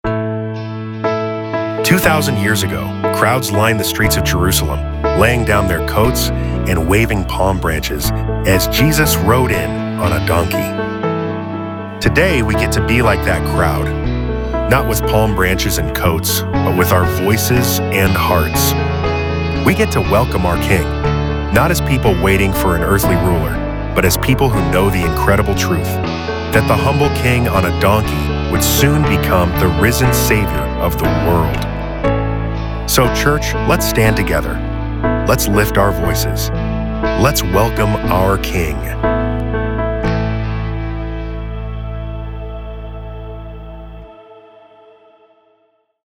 CallToWorship_PalmSunday_BAND.mp3